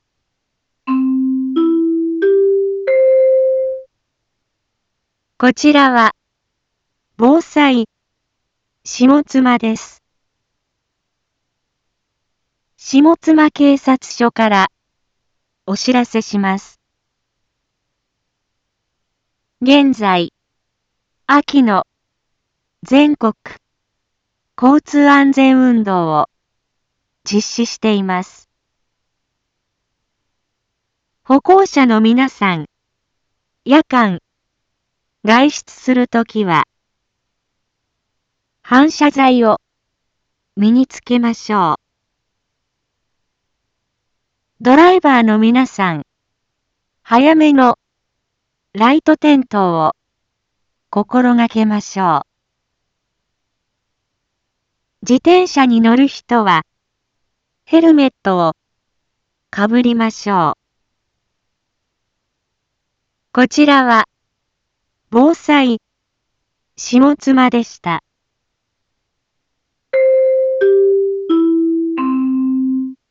Back Home 一般放送情報 音声放送 再生 一般放送情報 登録日時：2025-09-21 17:31:33 タイトル：交通事故防止広報 インフォメーション：こちらは、ぼうさいしもつまです。